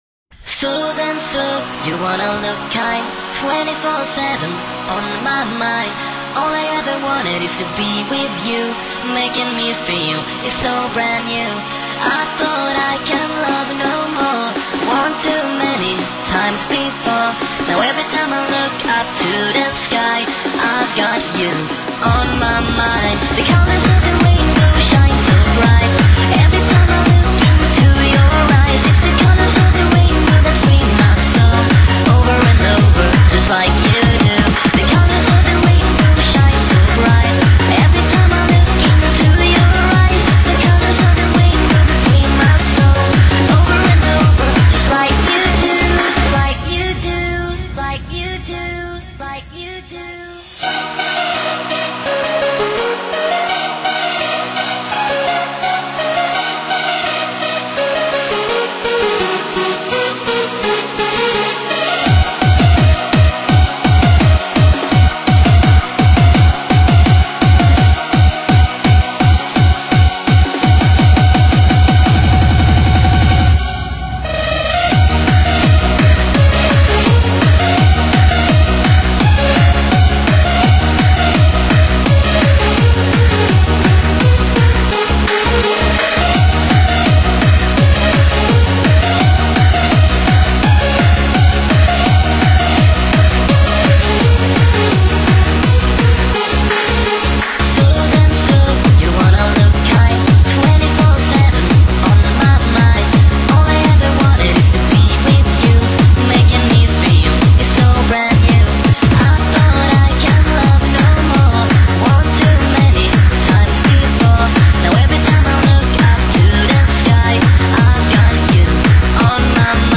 栏目： 现场串烧